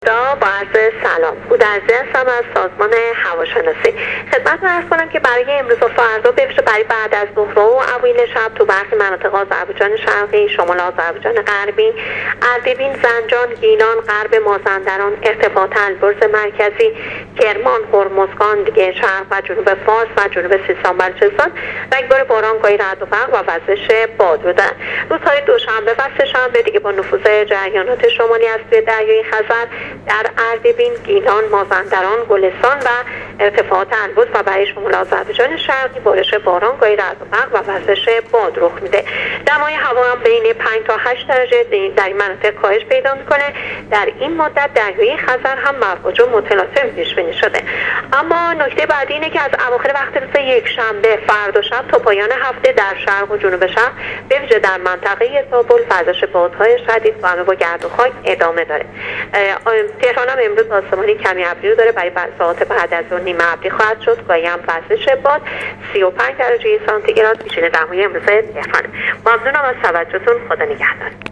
گزارش رادیو اینترنتی از آخرین وضعیت آب و هوای ۱۱ مرداد